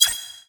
notification-disable.mp3